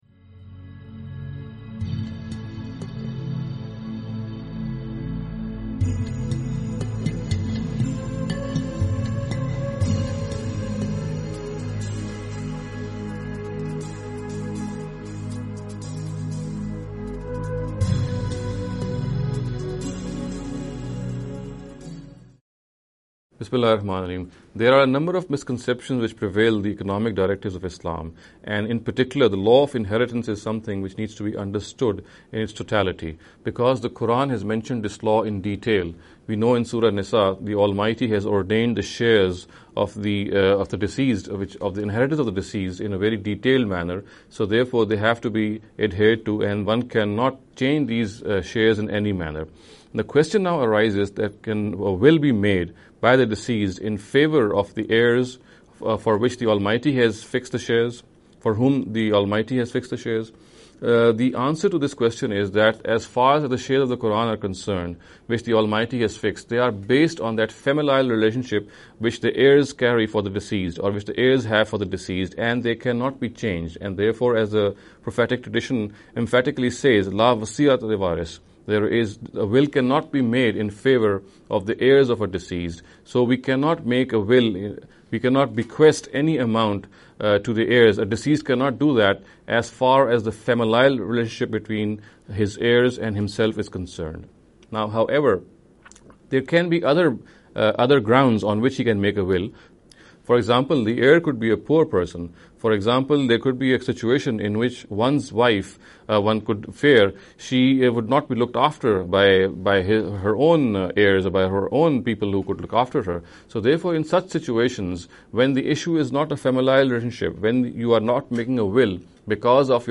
This lecture series will deal with some misconception regarding the Economic Directives of Islam.